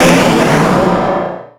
Cri de Méga-Kangourex dans Pokémon X et Y.
Cri_0115_Méga_XY.ogg